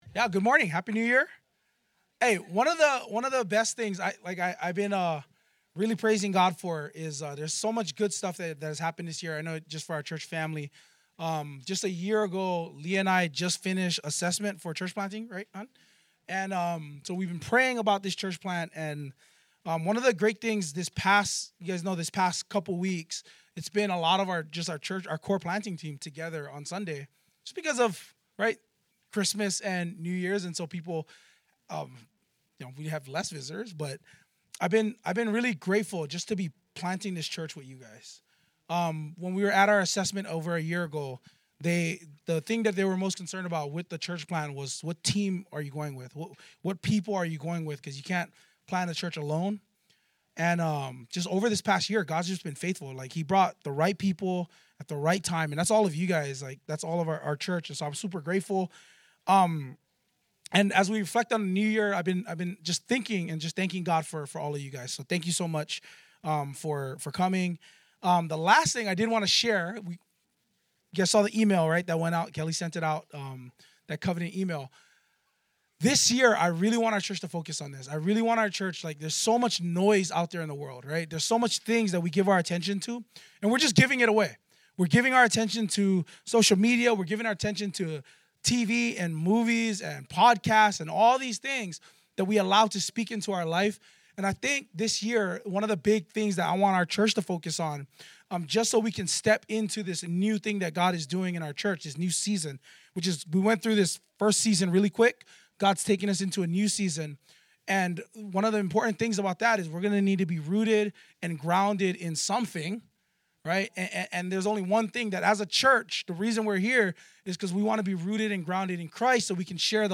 2023 God Became a Friend Preacher